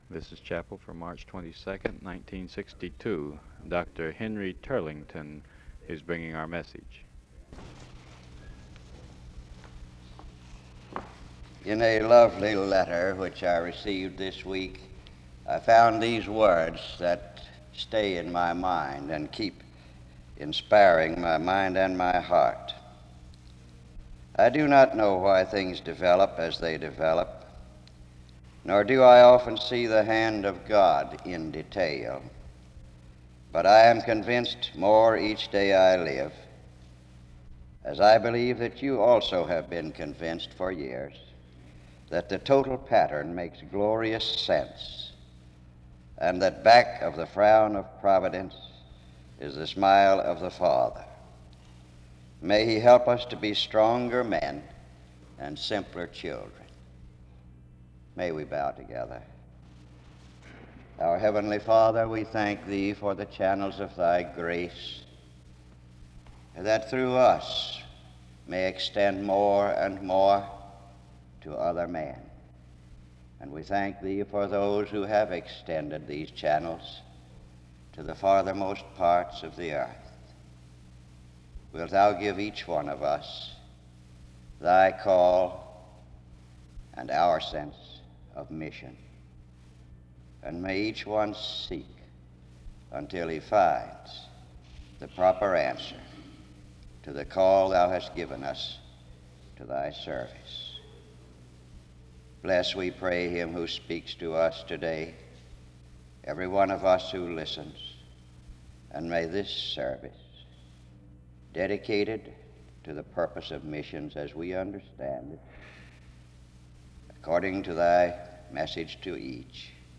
The service begins with a brief word (00:00-00:56) and prayer (00:57-02:12). A brief highlight is placed on missions as the core focus of the seminary (02:13-03:42).